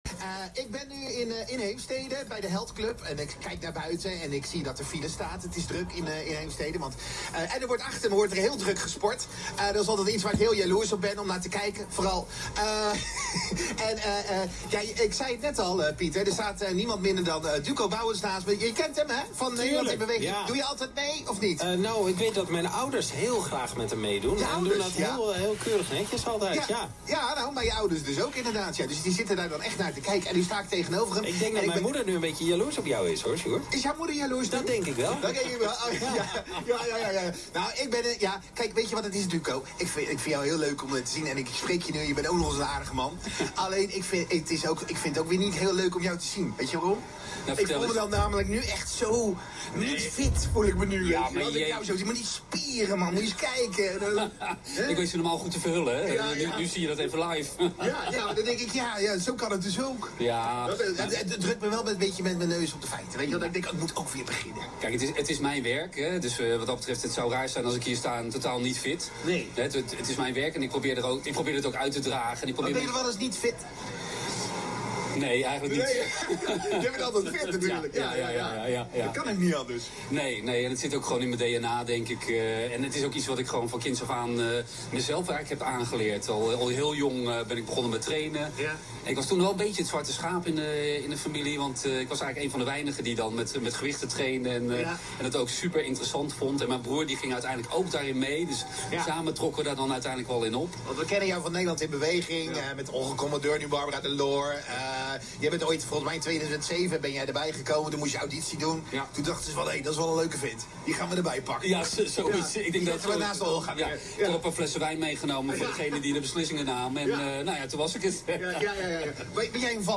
NH Radio live op locatie